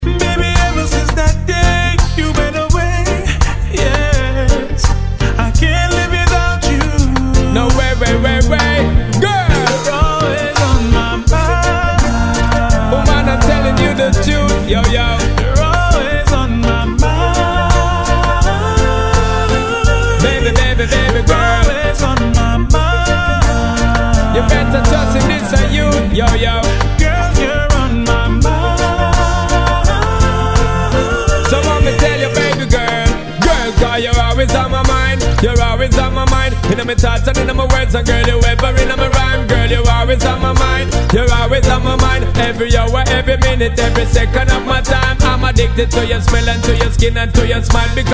鬼鉄板レゲエ！
Tag       REGGAE REGGAE